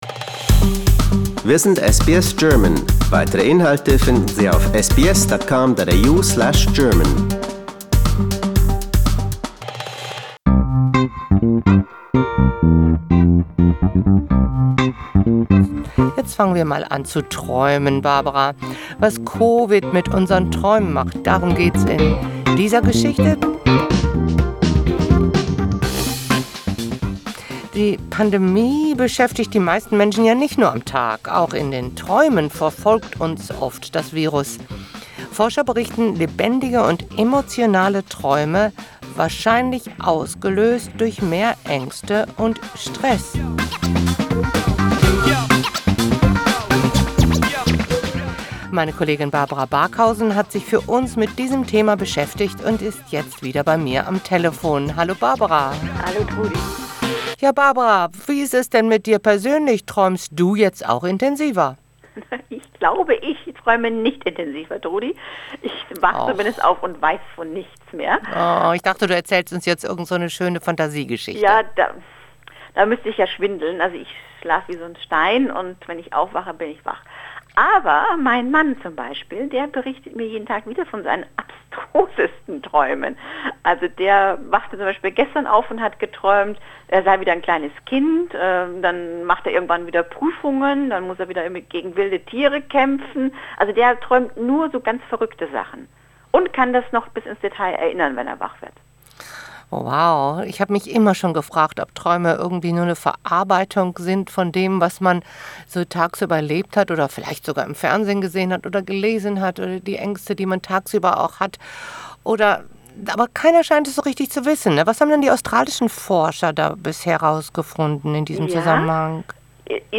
am Telefon